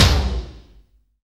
Index of /90_sSampleCDs/Roland LCDP01 Drums and Cymbals/TOM_E.Toms 1/TOM_E.Toms 1
TOM FUNK 0GL.wav